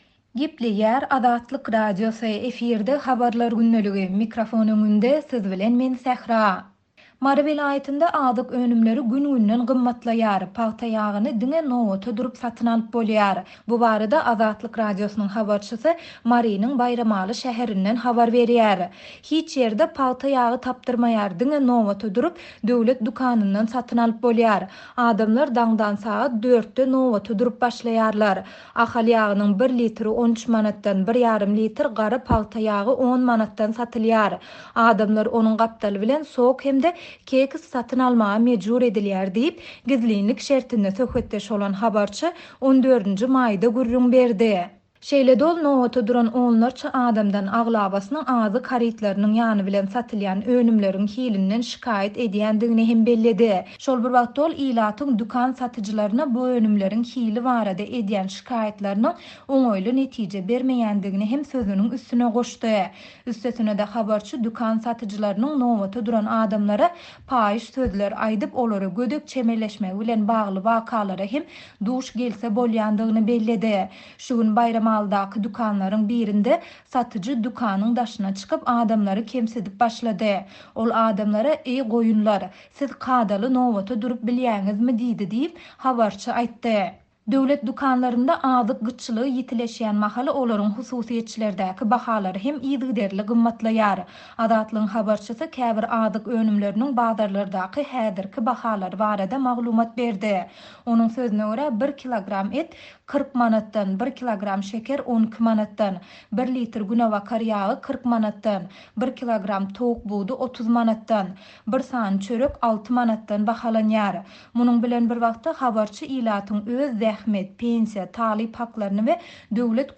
Mary welaýatynda azyk önümleri gün-günden gymmatlaýar. Pagta ýagyny diňe nobata durup satyn alyp bolýar. Döwlet dükanlarynda gyt azyklaryň ýany bilen satylýan harytlar çagalarda allergiýa döredýär. Bu barada Azatlyk Radiosynyň habarçysy Marynyň Baýramaly şäherinden habar berýär.